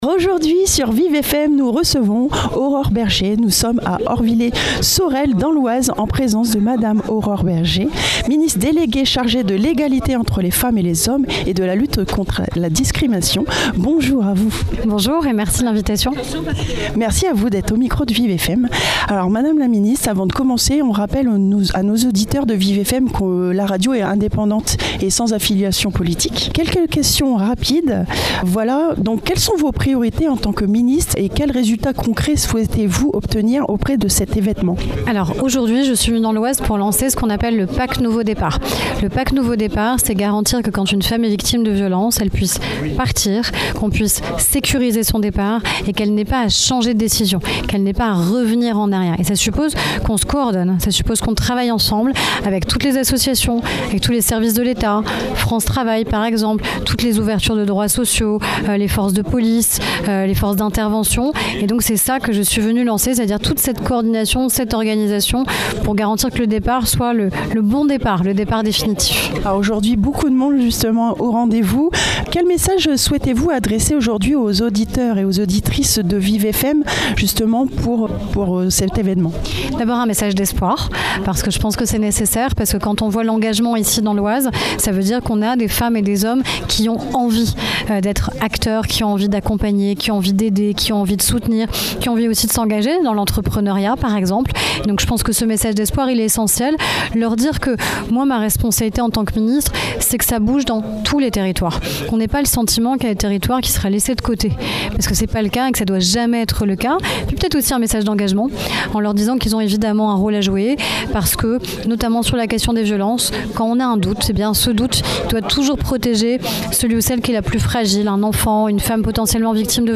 30 ITW MADAME LA MINISTRE AURORE BERGE.
30-ITW-MADAME-LA-MINISTRE-AURORE-BERGE.mp3